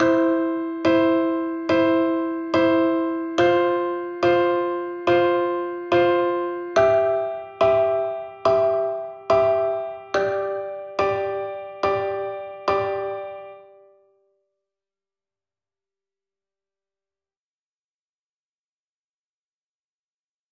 para flauta, y xilófonos.